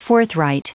Prononciation du mot forthright en anglais (fichier audio)
Prononciation du mot : forthright